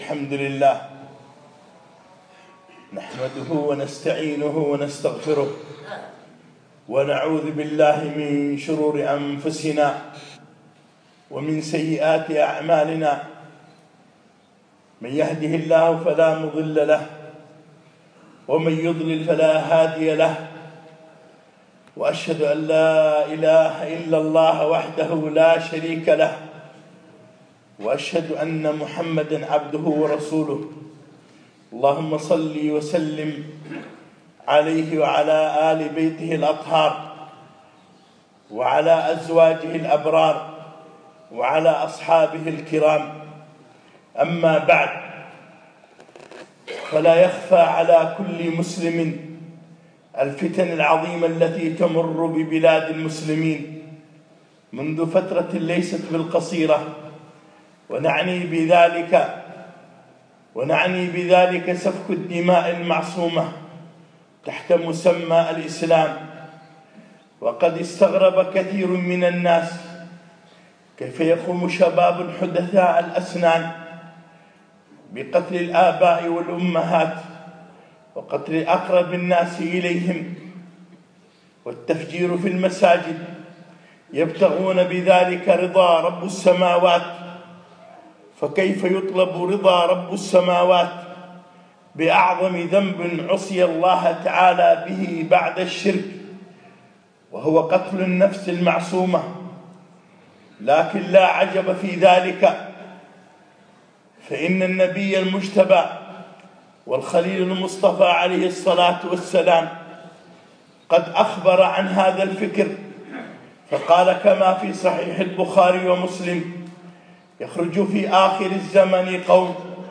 خطبة - أصول الخوارج